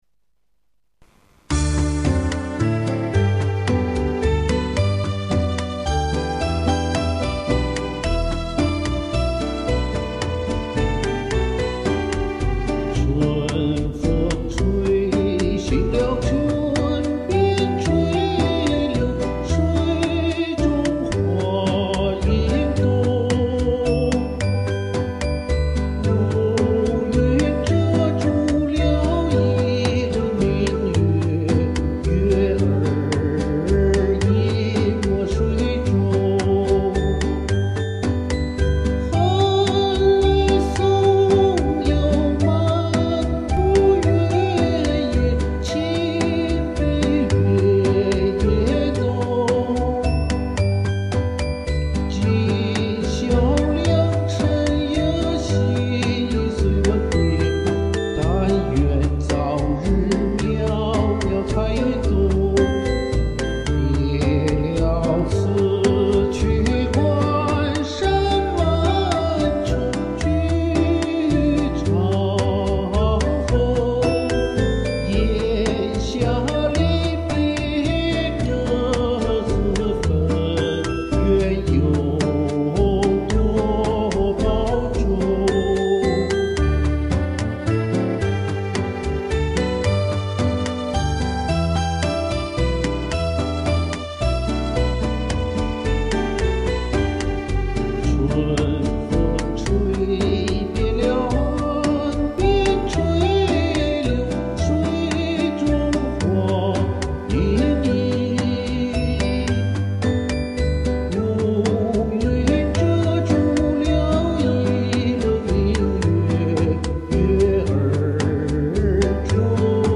一个老知青演唱